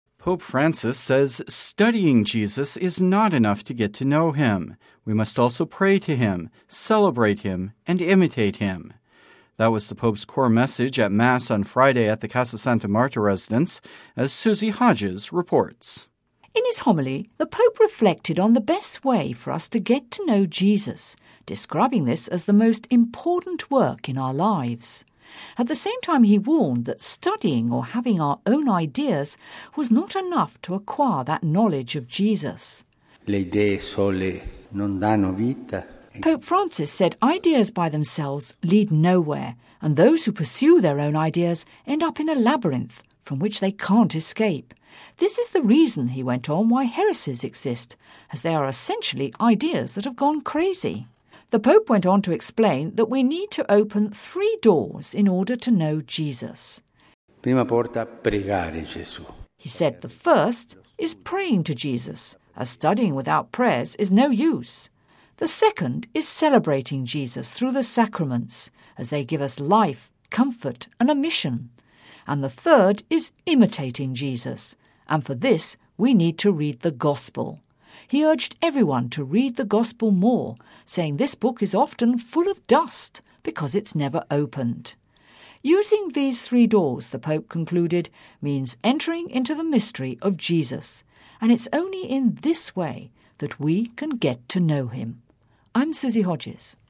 (Vatican Radio) Pope Francis says studying Jesus is not enough to get to know him, we must also pray to him, celebrate him and imitate him. This was the Pope’s core message at Mass on Friday in the Santa Marta residence.